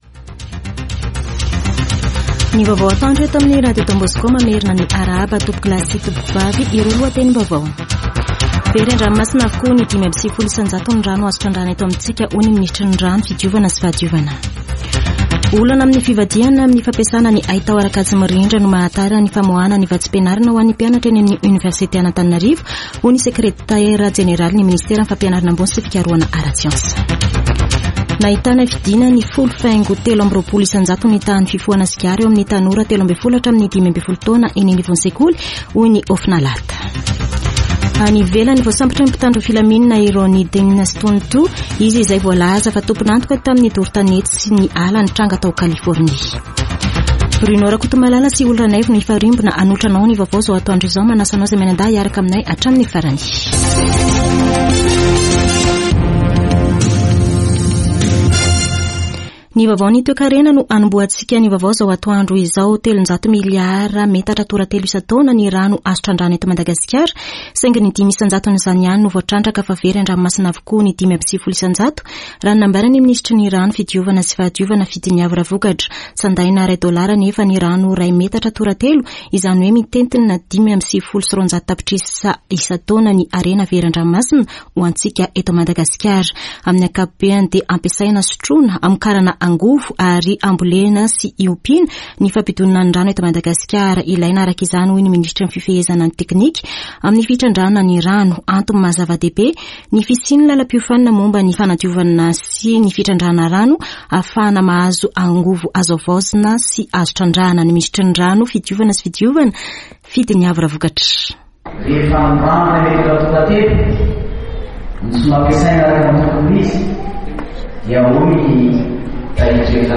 [Vaovao antoandro] Sabotsy 27 jolay 2024